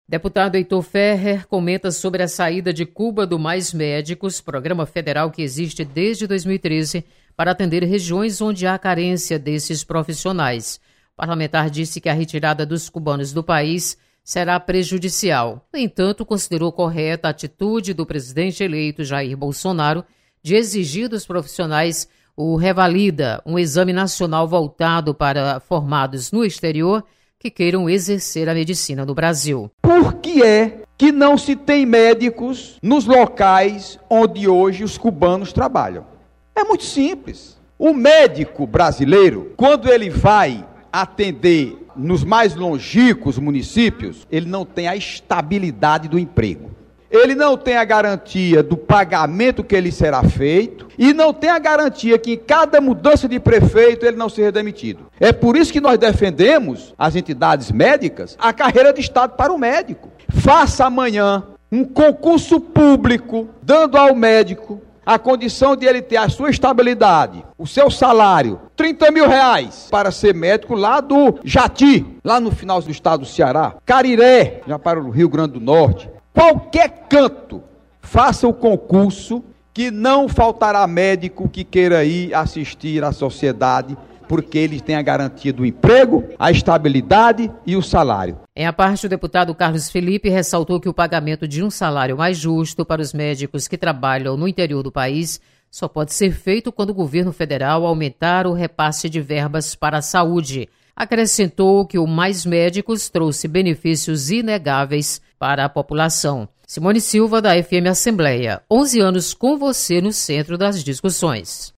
Deputado Heitor Férrer comenta sobre saída dos médicos cubanos do Mais Médicos.